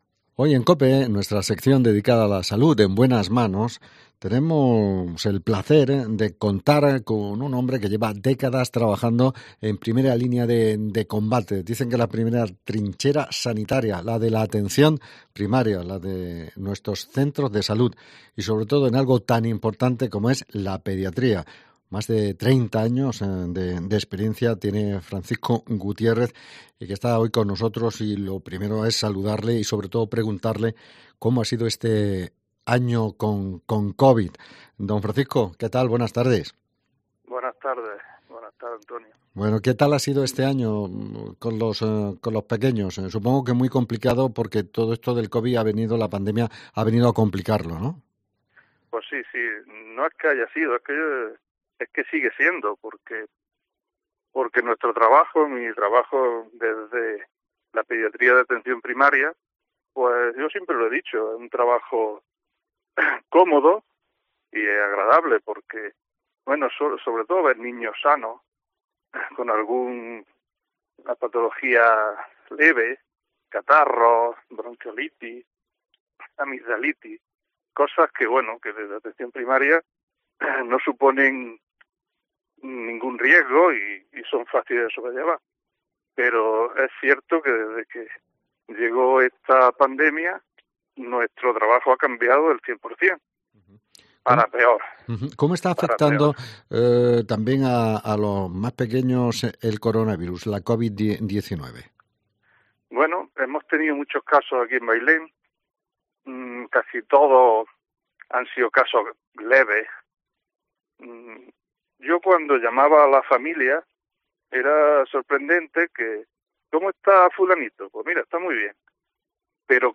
Entrevista con el pediatra